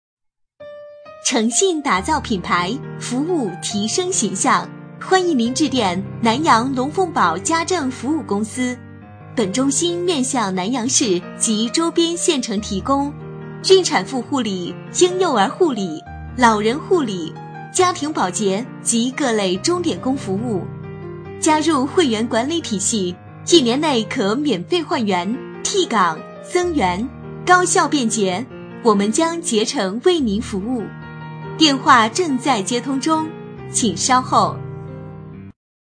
【女23号彩铃】龙凤宝家政彩铃
【女23号彩铃】龙凤宝家政彩铃.mp3